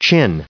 chin_en-us_recite_stardict.mp3